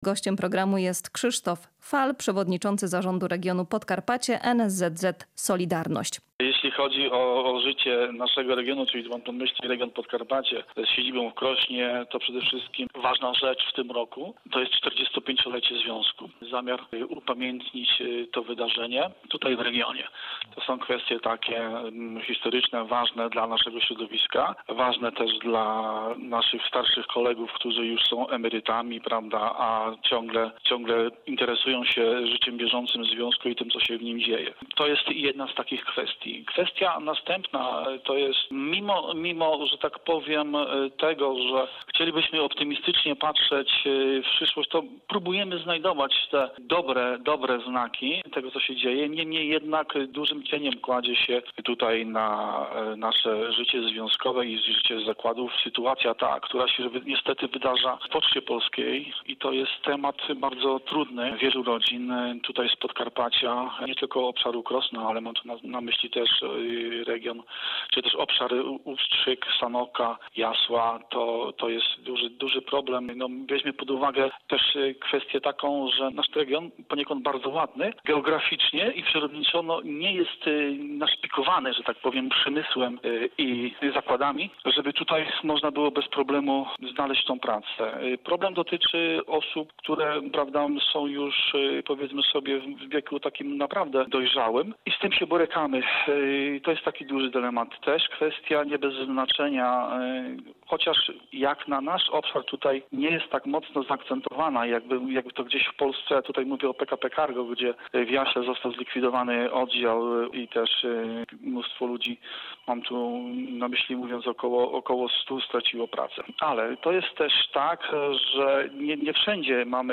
Każde miejsce pracy na wagę złota” – rozmowa